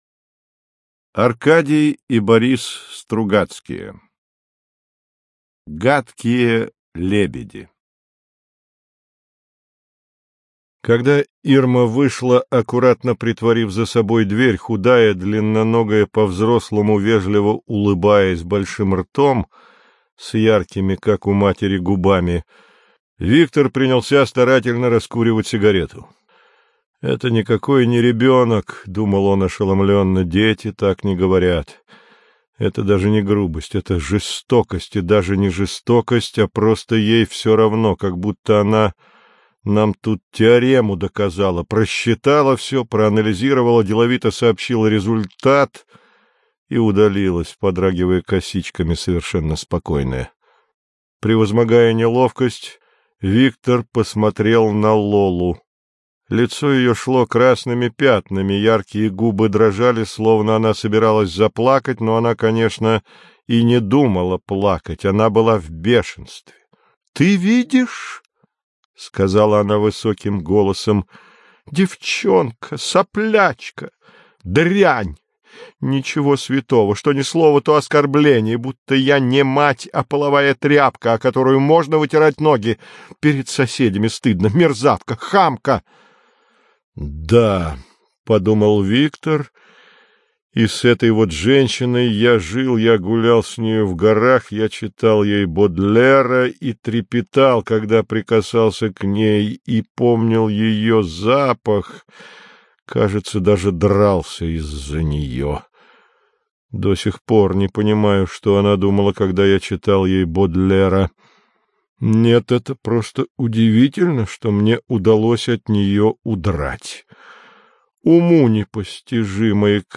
Аудиокнига Гадкие лебеди | Библиотека аудиокниг